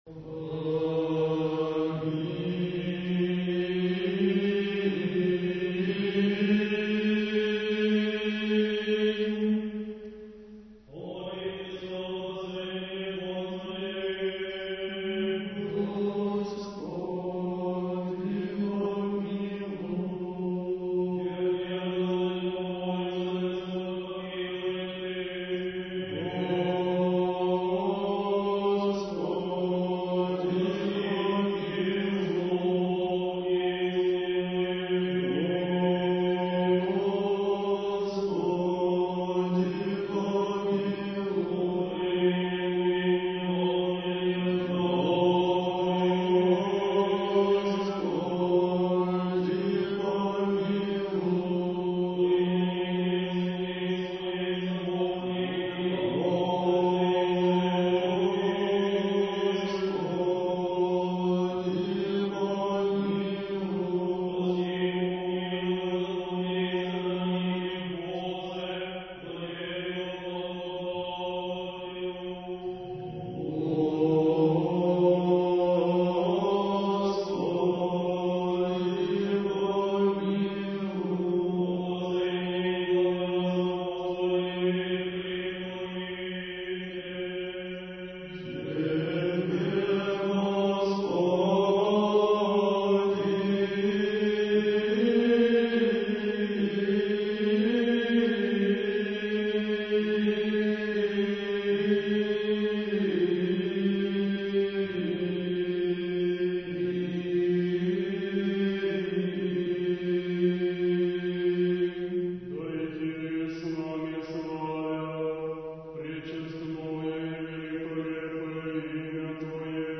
Духовная музыка / Русская